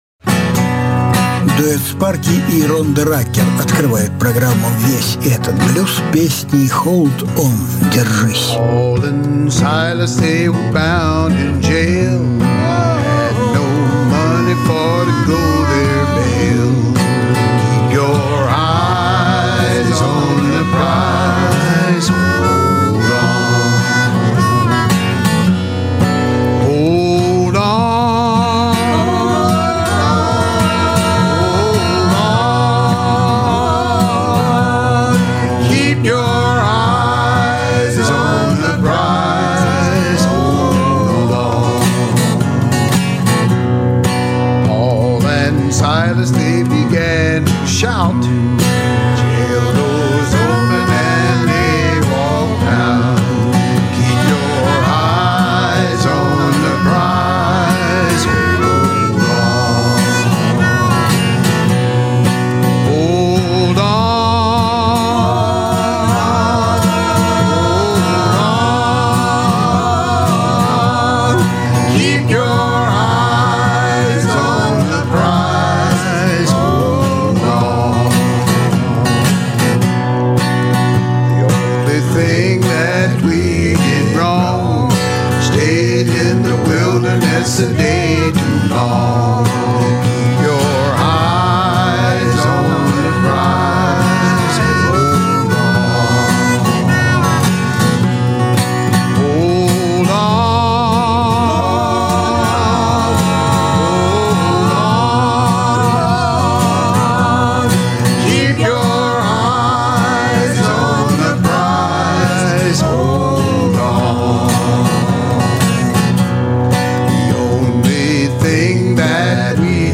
это муж и жена фолк-музыкальный дуэт из Теннесси.
Жанр: Блюзы и блюзики